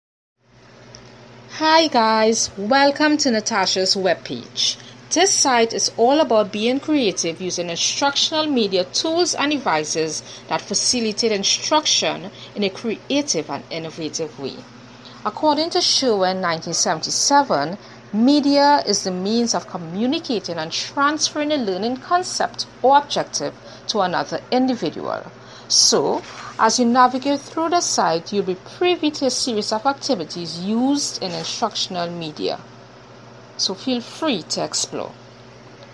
扭曲的TR8
描述：TR8节拍穿过Mooer失真踏板。
Tag: rhythmus-SV 失真 节奏 敲击环